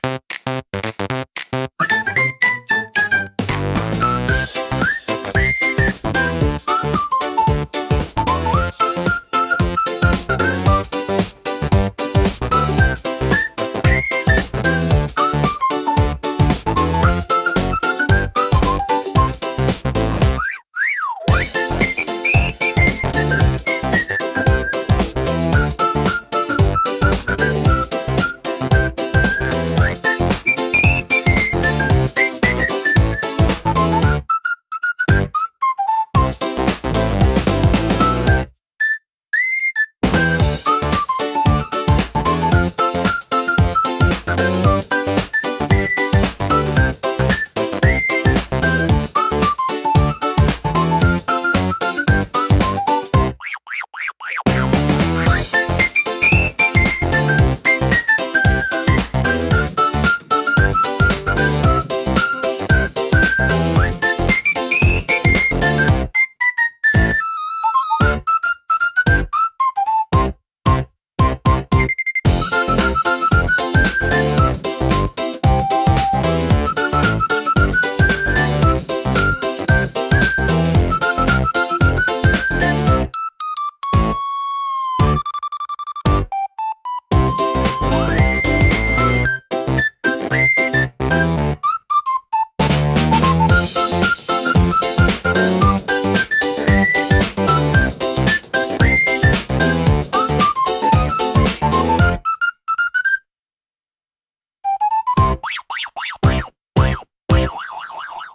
軽快なリズムと遊び心あふれるメロディが、聴く人に楽しさと自由なエネルギーを届けます。
口笛を主体にした明るく活発な楽曲で、元気いっぱいの陽気な雰囲気が特徴です。